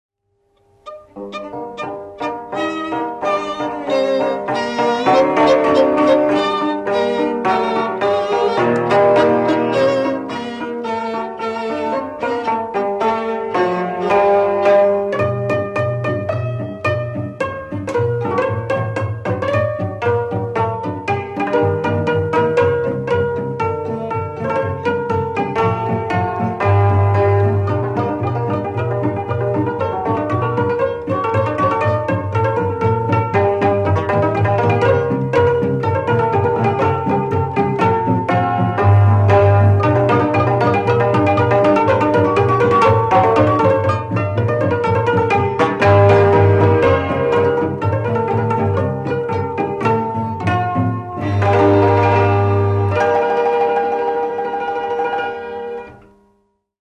Műfaj Csárdás
Hangszer Zenekar
Helység Sopron
Gyűjtő(k) Lajtha László